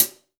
Alchemist HiHat 10.wav